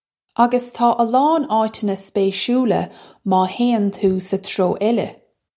Pronunciation for how to say
Uggus taw uh lawn aw-chunna spay-shoola maw hay-un too suh tro ella.
This is an approximate phonetic pronunciation of the phrase.